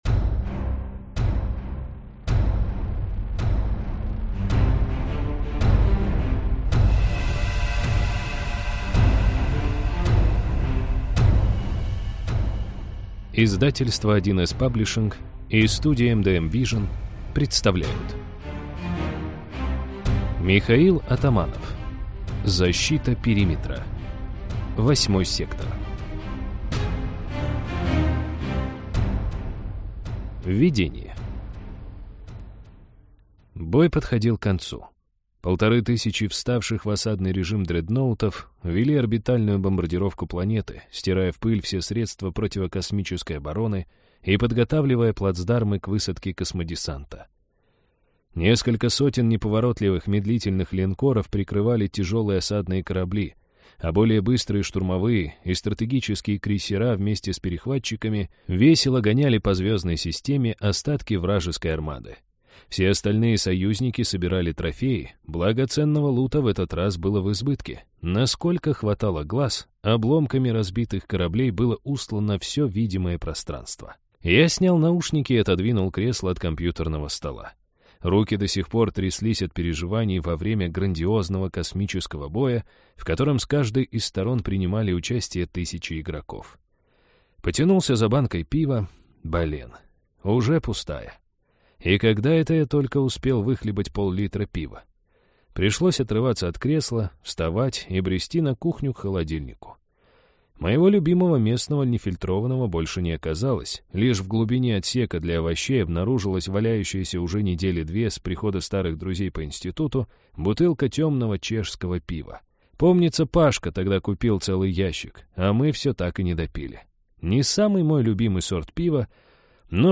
Аудиокнига Защита Периметра. Восьмой сектор | Библиотека аудиокниг